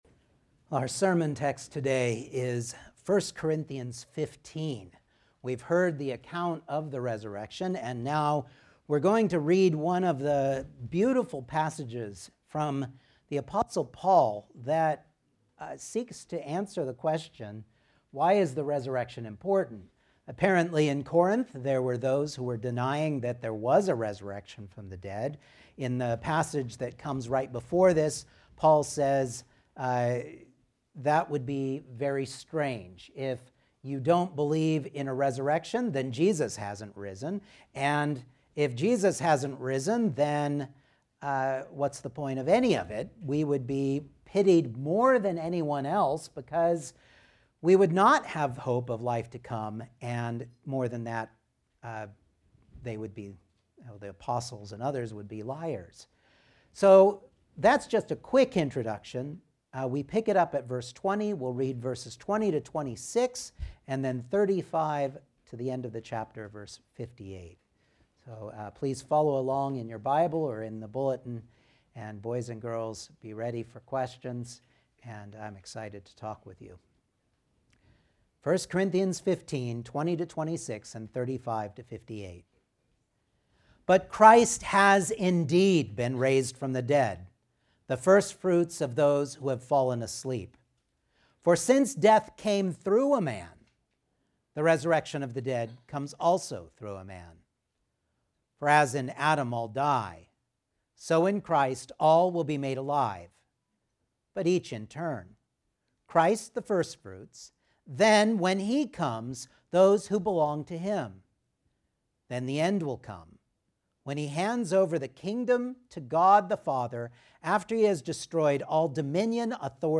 1 Corinthians 15:20-26,35-58 Service Type: Sunday Morning I. The Two Adams II.